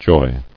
[joy]